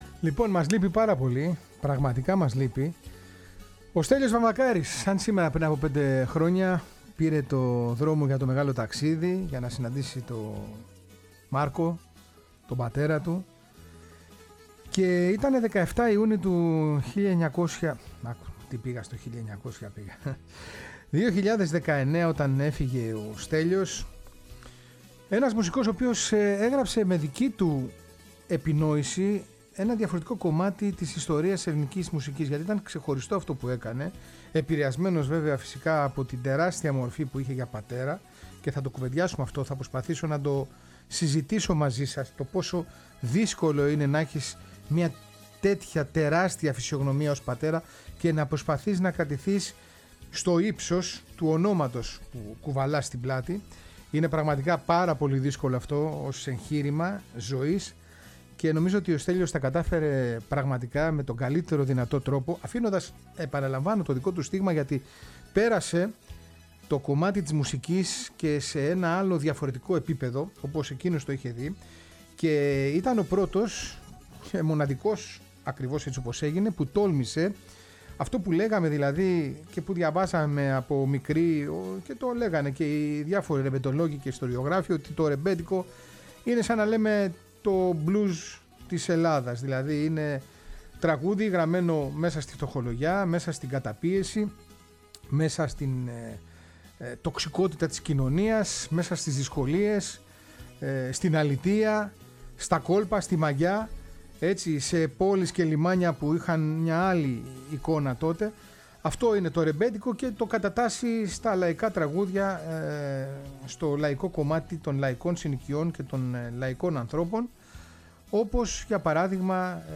Ένα ανεπανάληπτο ταξίδι στη γνήσια μαγκιά του Πειραιά και στους λαϊκούς δρόμους των blues με μπουζούκι και μπαγλαμά.
ΡΕΜΠΕΤΙΚΟ